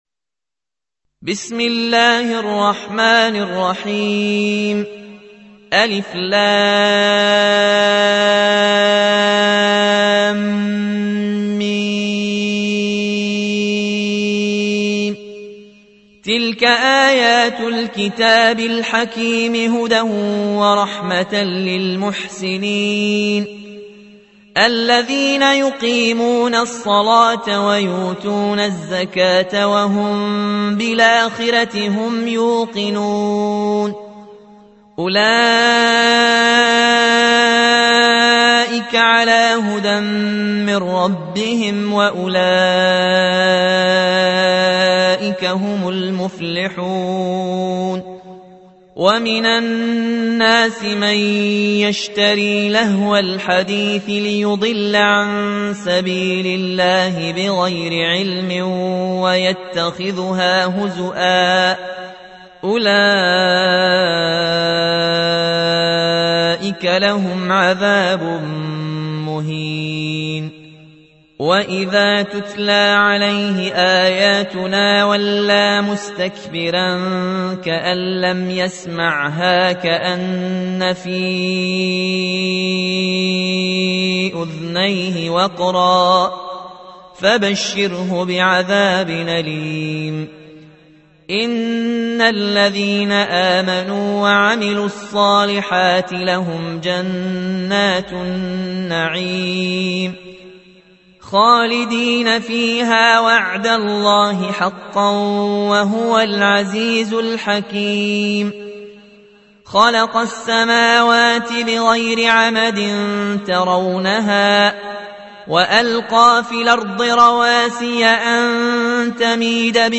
تحميل : 31. سورة لقمان / القارئ ياسين الجزائري / القرآن الكريم / موقع يا حسين